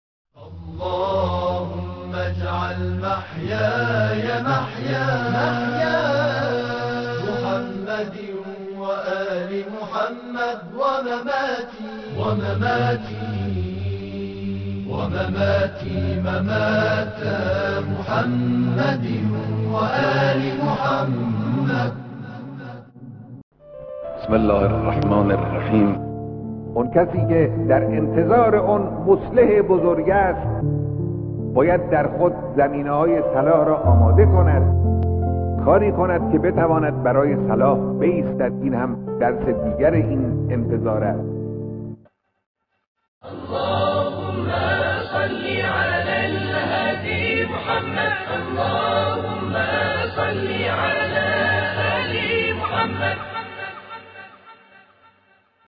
• امام مهدی, نوای مهدوی, نوای اسلامی, امام زمان, صوت بیانات رهبر انقلاب اسلامی, نوای بیانات مقام معظم رهبری